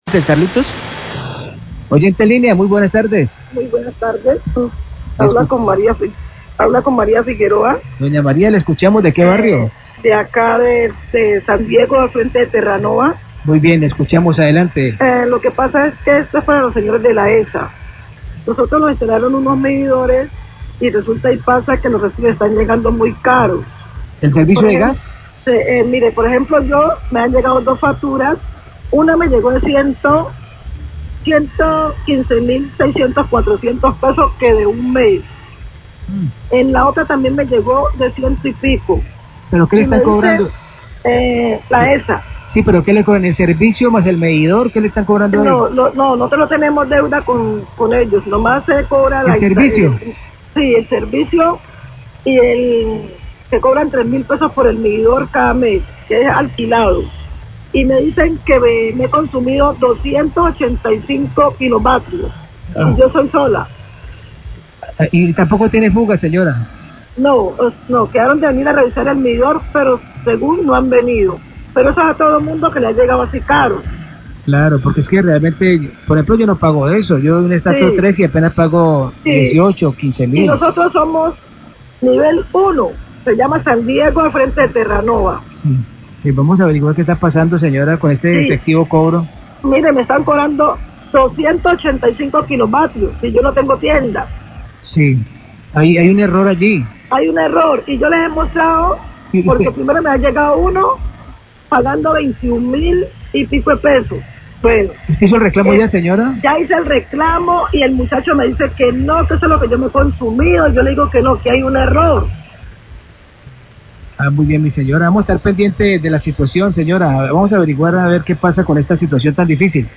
OYENTE SE QUEJA DEL ALTO COSTO DE LA FACTURA DE ENERGIA, NOTICIAS DE CALIDAD, 1250pm
Radio